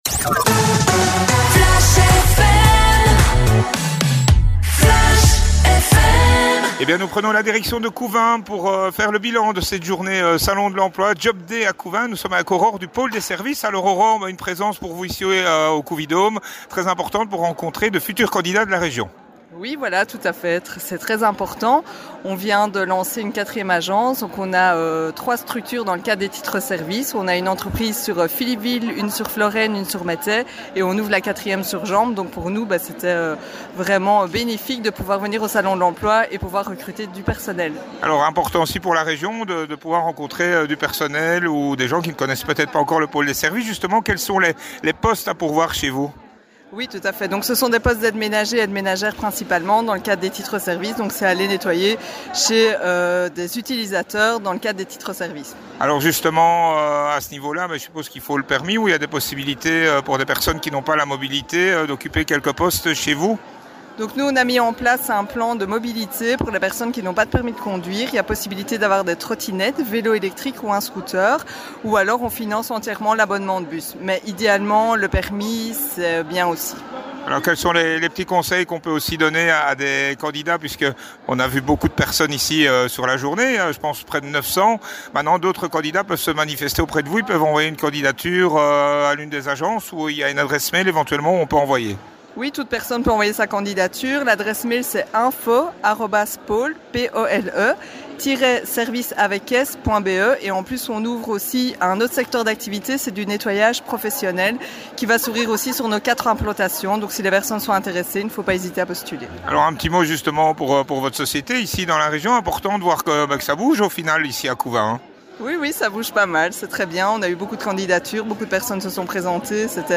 Les interviews sur le JOB DAY à COUVIN le 23 avril 2026
Au micro de Flash fm :